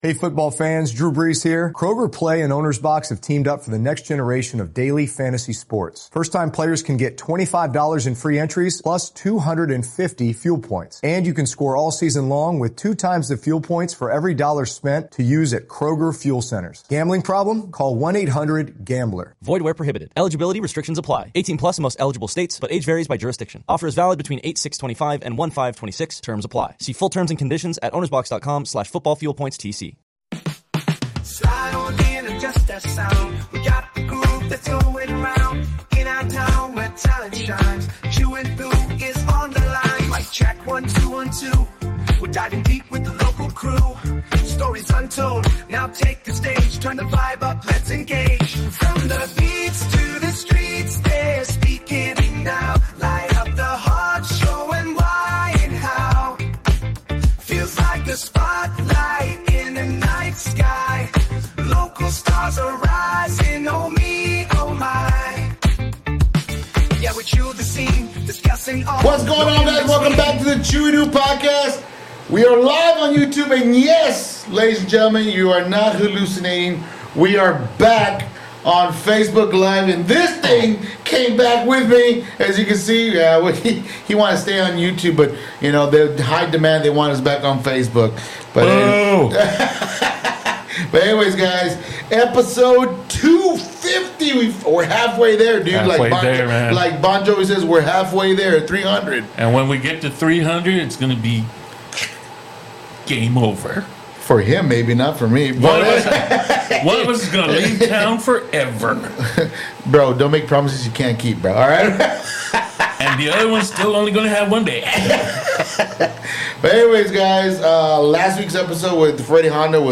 You won't want to miss this inspiring conversation!